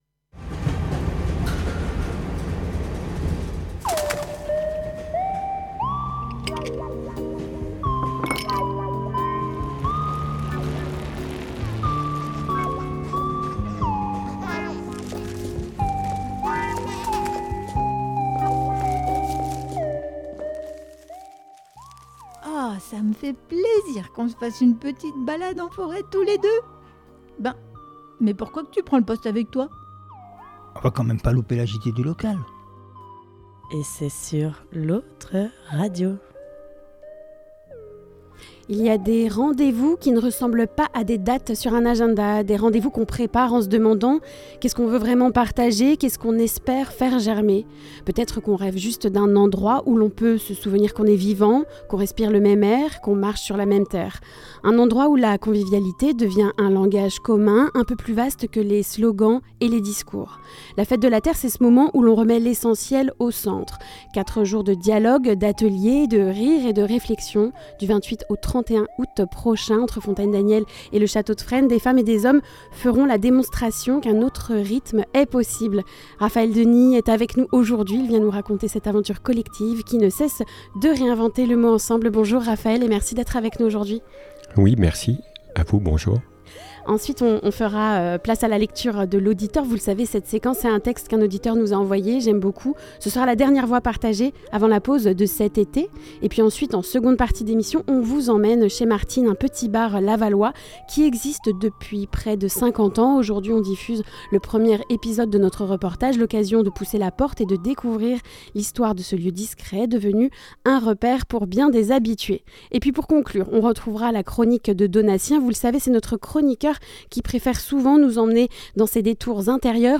La lecture de l'auditeur Le reportage